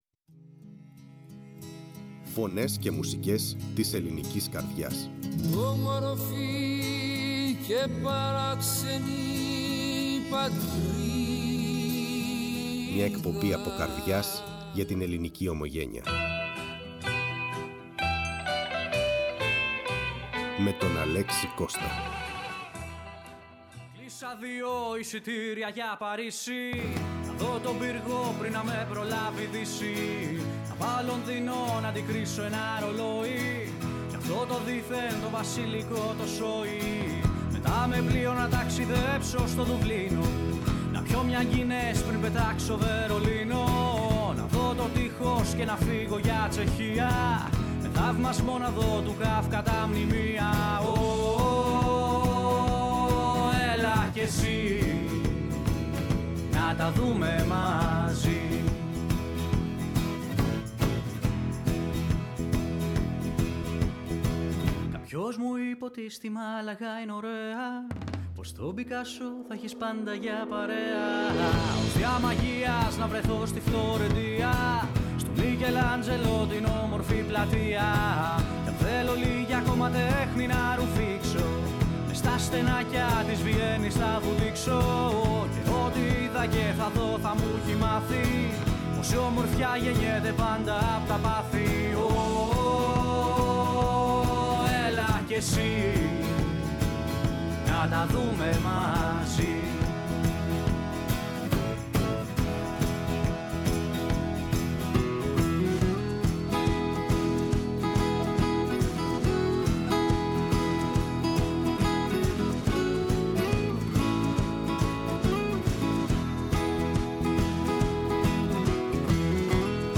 Ακούσαμε αποσπάσματα από αγαπημένα του έργα και συζητήσαμε για τη δημιουργική του πορεία, τις επιρροές του και τις συνεργασίες του με σημαντικούς μουσικούς.
Μια εκπομπή γεμάτη ατμόσφαιρα, ένταση και ζωντάνια, που μας έκανε να ταξιδέψουμε στον κόσμο της μουσικής του..